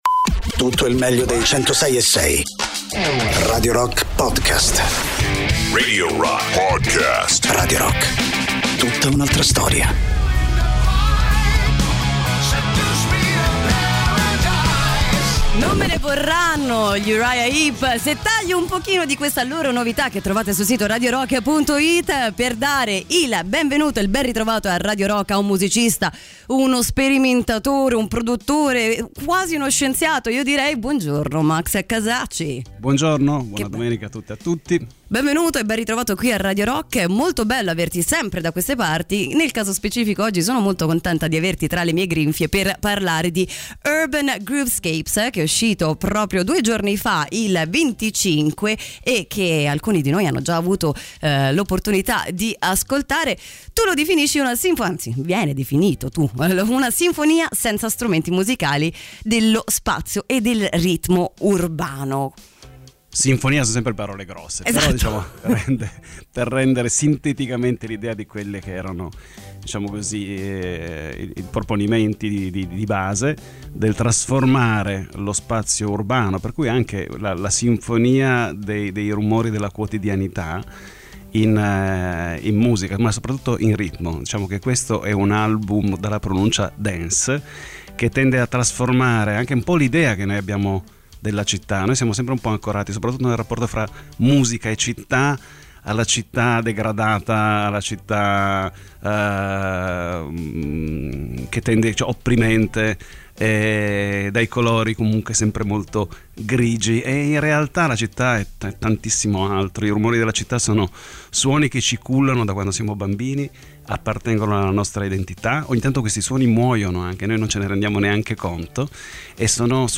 Interviste: Max Casacci (27-11-22)